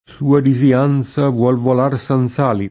senza [S$nZa] prep.